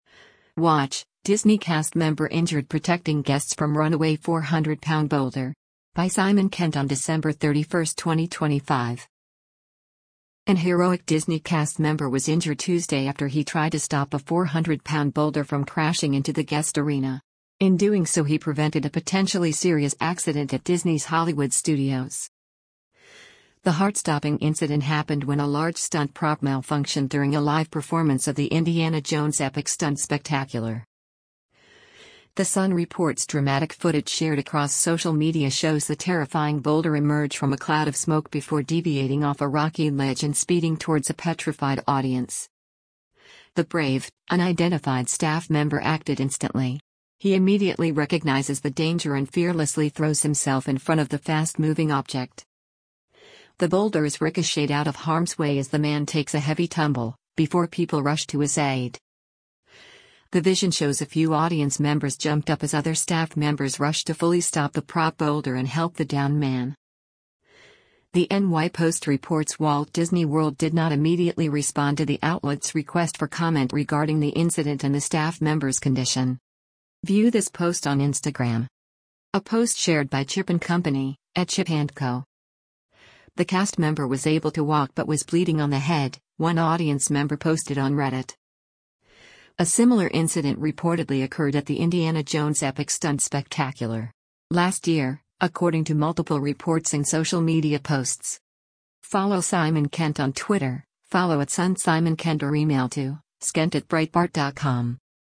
The heart-stopping incident happened when a large stunt prop malfunctioned during a live performance of the Indiana Jones Epic Stunt Spectacular!.